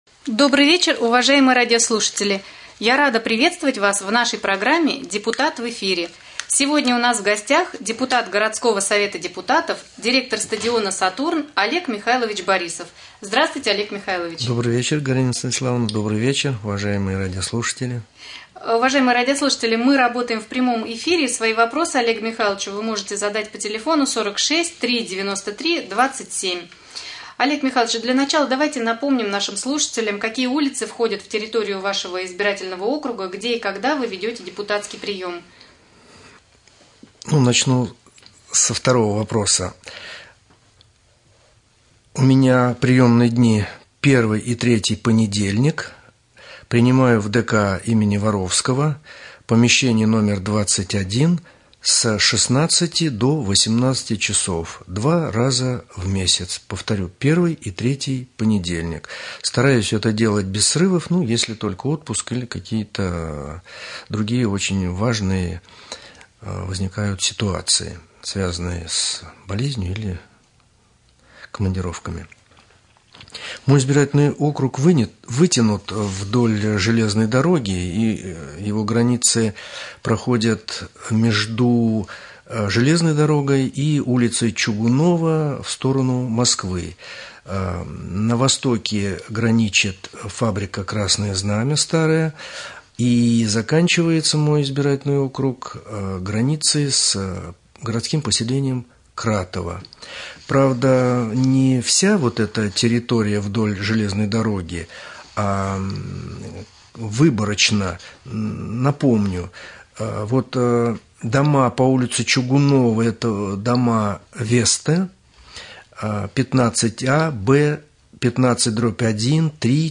Прямой эфир с депутатом Совета депутатов городского поселения Раменское Олегом Михайловичем Борисовым.